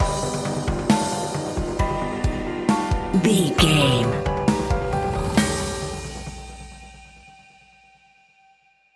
Aeolian/Minor
chaotic
eerie
haunting
piano
synthesiser